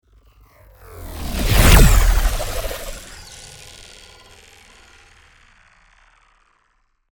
Download Sci Fi sound effect for free.
Sci Fi